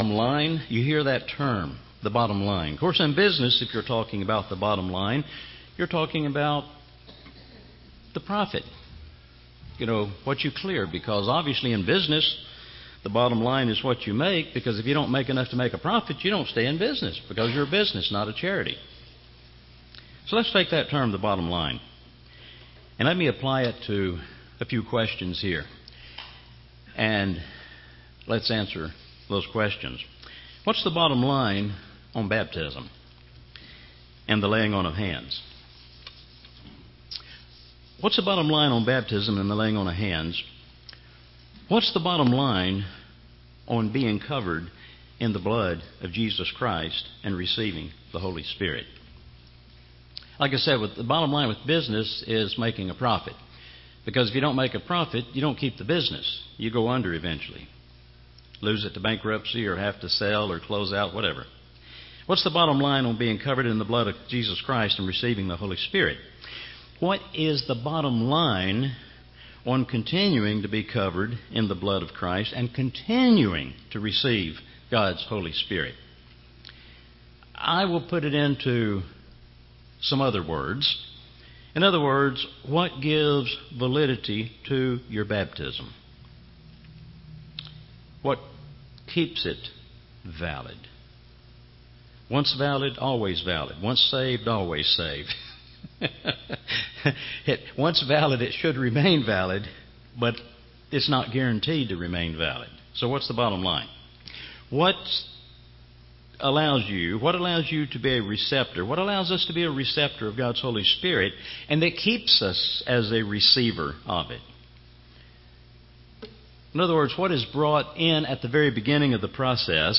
Our sin is never greater than the blood of Christ. Enjoy this wonderful sermon about obedience and repentance.